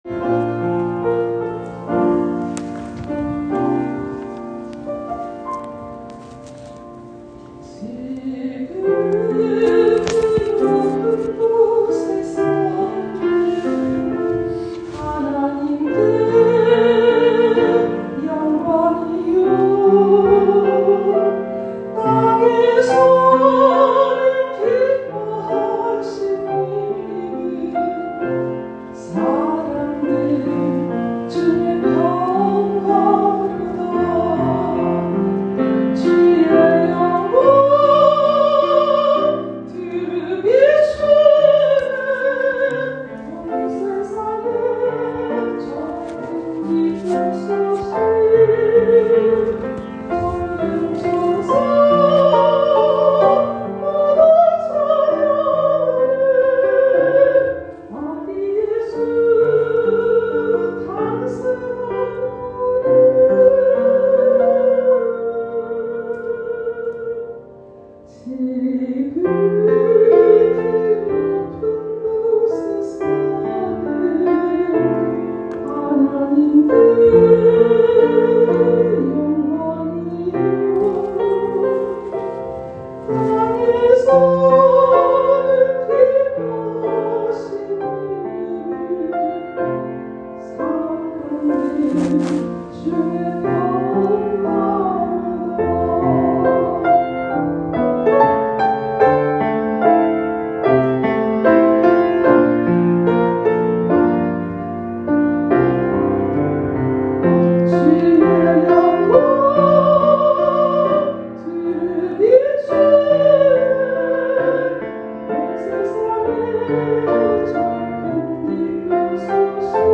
2021년 12월 25일 오전 11시 성탄절 예배 쥬빌리 찬양대
🎄Christmas Jubilee Choir
2021-Christmas-Jubilee-choir-.m4a